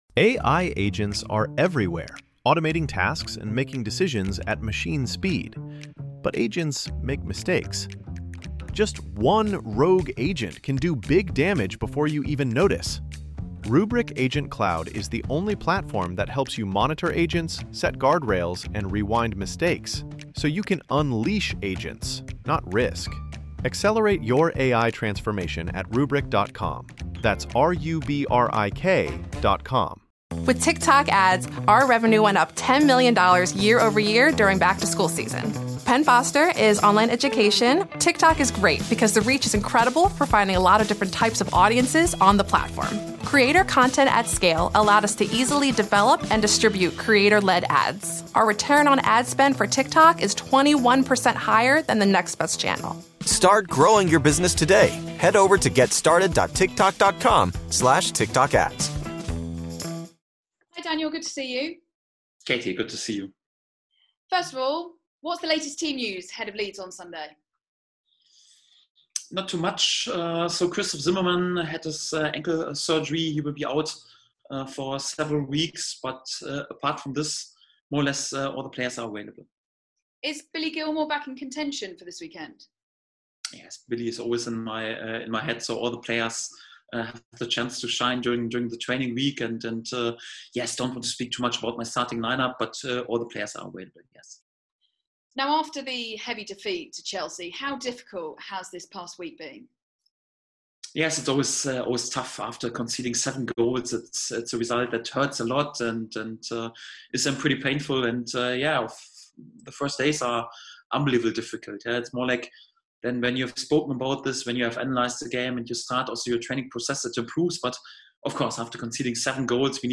"I don't need any backing" | Daniel Farke press conference ahead of Leeds | The Pink Un
Daniel Farke speaks to the media ahead of Sunday's Premier League match against Leeds United at Carrow Road.